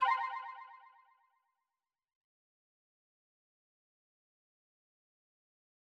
confirm_style_4_echo_001.wav